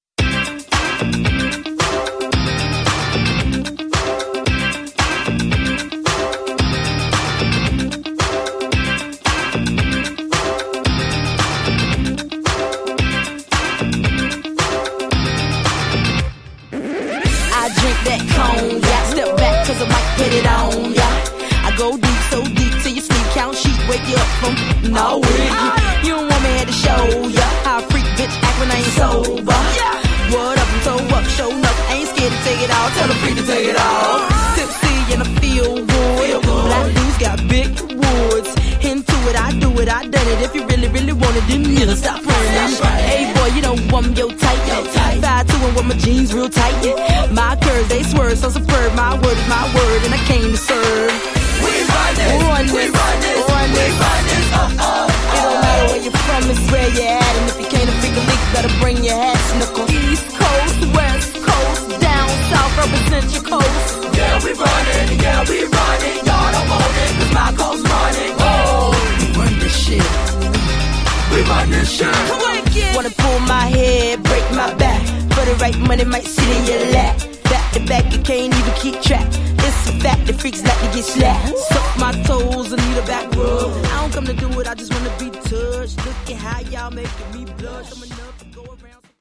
115 bpm
Dirty Version
B-Side blend I made a few months back.